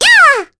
Mirianne-Vox_Attack4_kr.wav